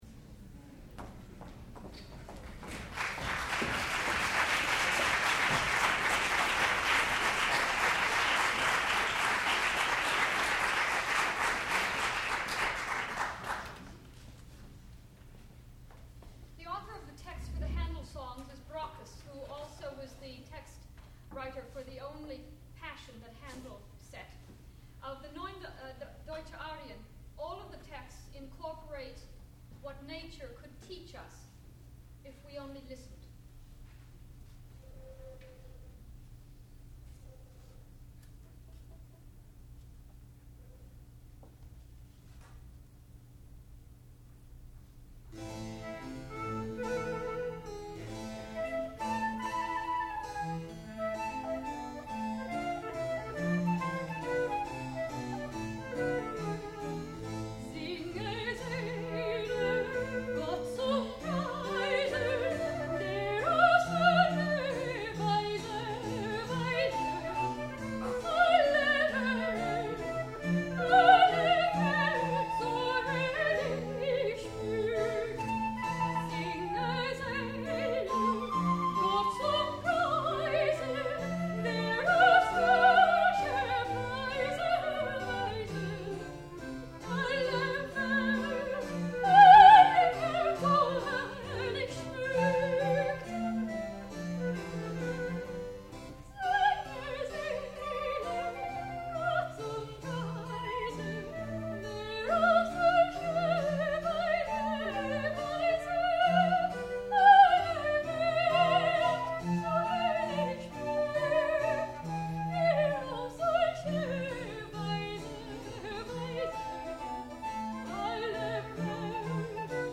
sound recording-musical
classical music
harpsichord
violoncello
flute
soprano